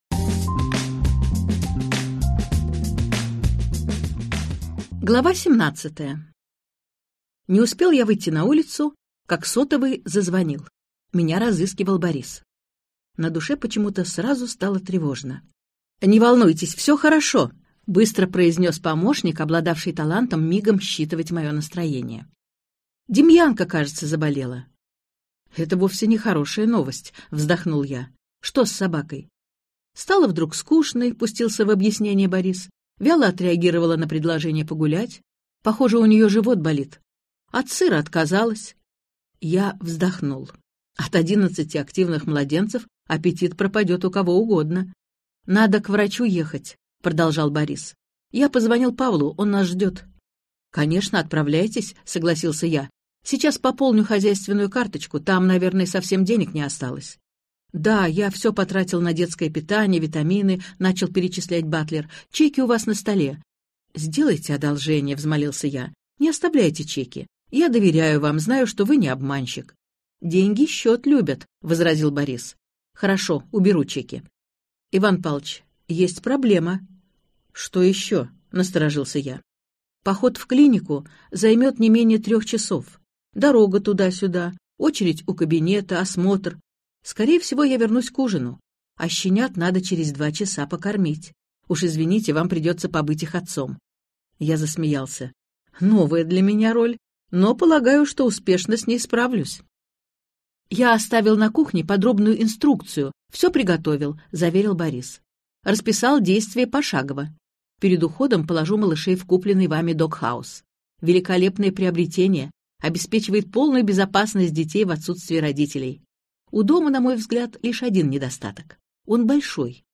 Аудиокнига Коронный номер мистера Х - купить, скачать и слушать онлайн | КнигоПоиск